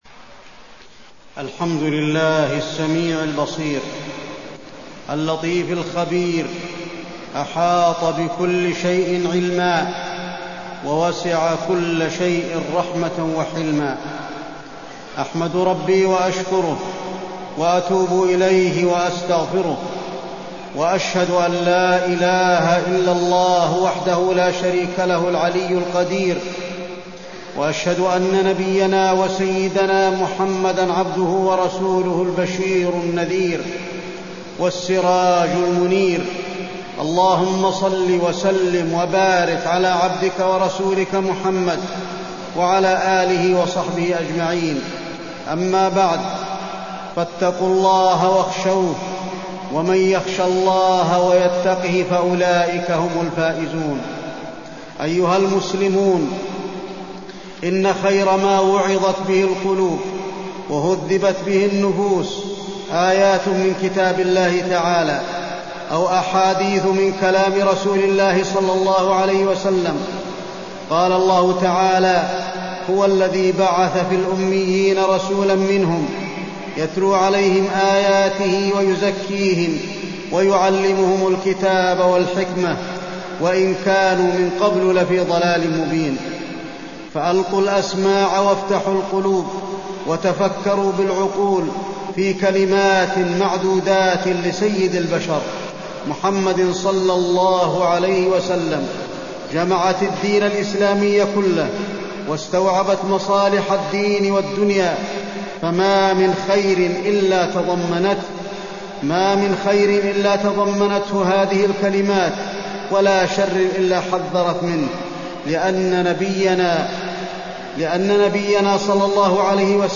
تاريخ النشر ٢٢ رجب ١٤٢٤ هـ المكان: المسجد النبوي الشيخ: فضيلة الشيخ د. علي بن عبدالرحمن الحذيفي فضيلة الشيخ د. علي بن عبدالرحمن الحذيفي النصيحة